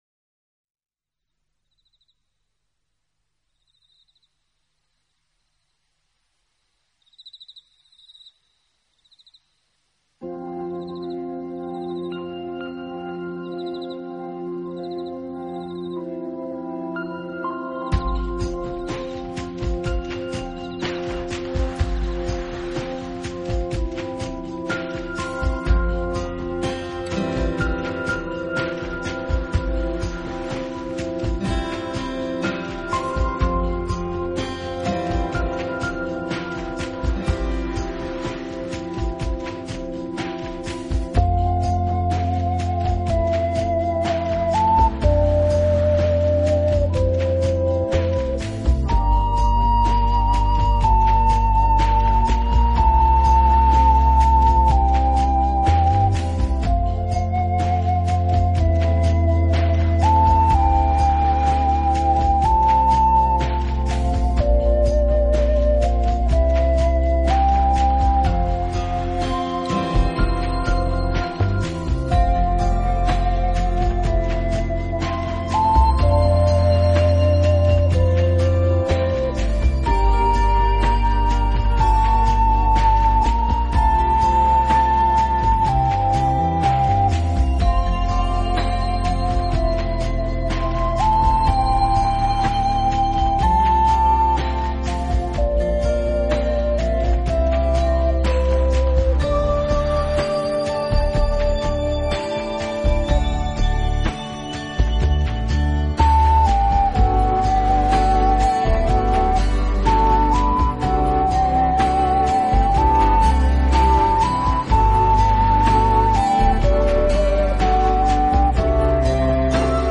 音乐类型：New Age
无论你喜欢清新淡雅的音乐小品，还是荡气回肠的大气之作，都会在这张专辑中找到你的所爱。